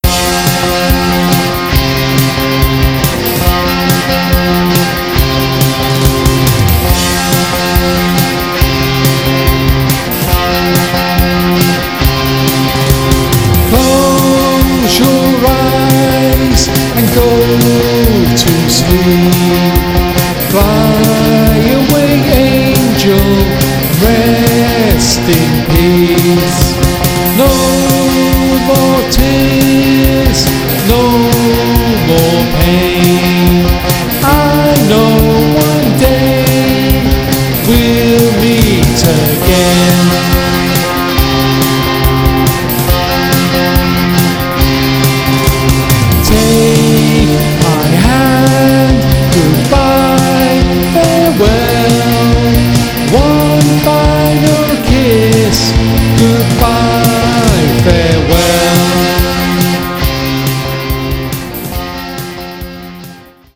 an up tempo guitar version